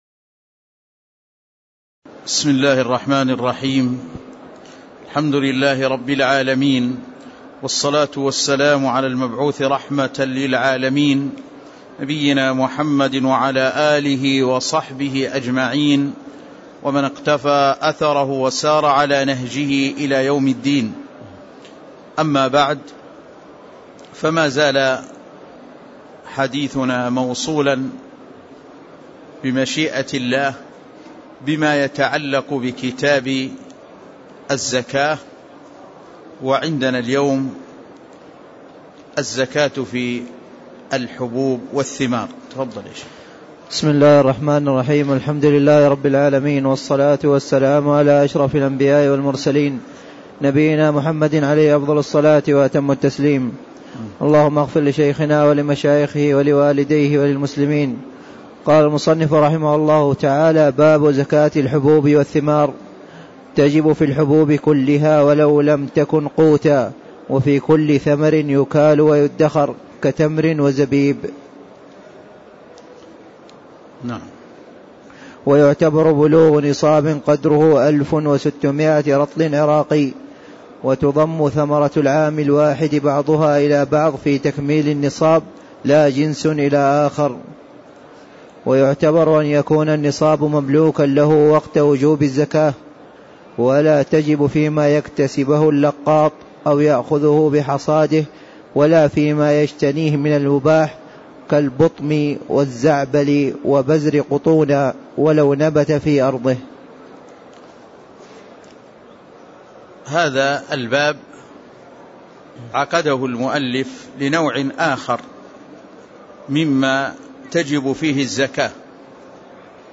تاريخ النشر ١٥ ربيع الثاني ١٤٣٦ هـ المكان: المسجد النبوي الشيخ